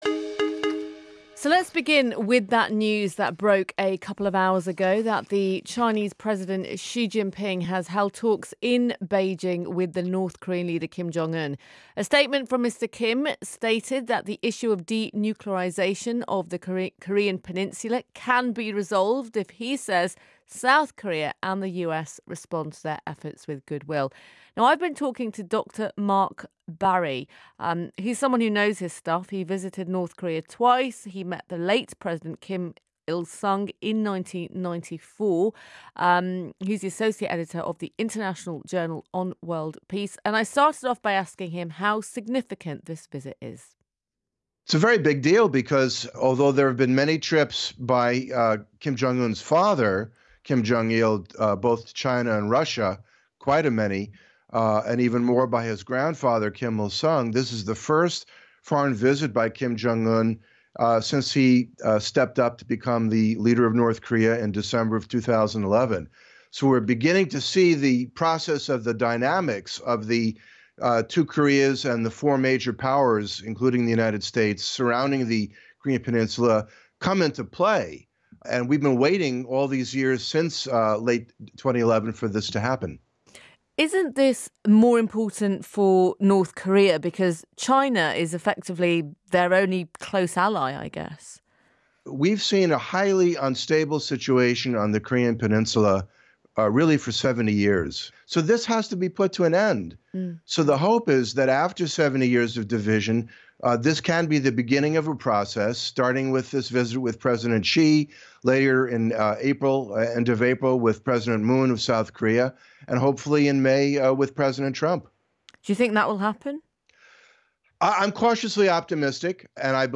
clip-bbc-world-service-newsday-chinese-and-north-korean-leaders-meet-in-beijing.m4a